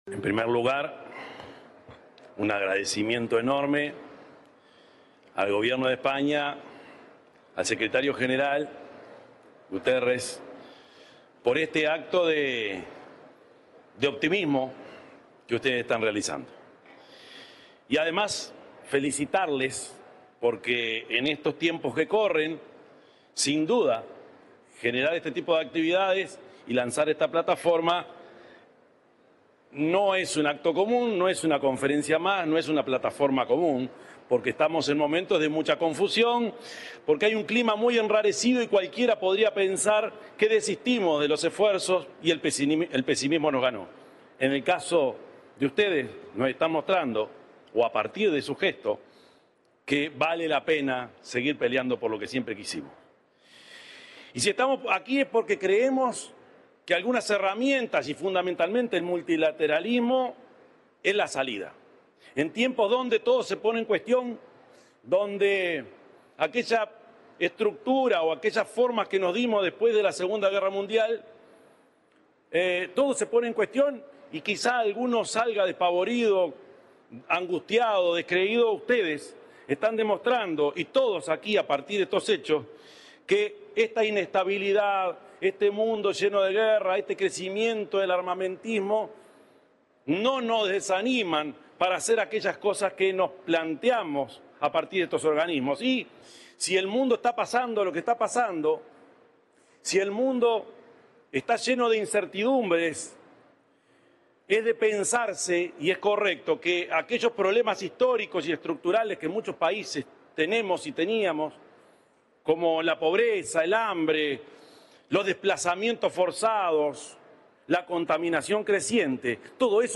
Palabras del presidente Orsi en 4.ª Conferencia Internacional sobre Financiación para el Desarrollo
Palabras del presidente Orsi en 4.ª Conferencia Internacional sobre Financiación para el Desarrollo 30/06/2025 Compartir Facebook X Copiar enlace WhatsApp LinkedIn El presidente de la República, profesor Yamandú Orsi, disertó en la 4.ª Conferencia Internacional sobre Financiación para el Desarrollo, que se realiza en Sevilla, España.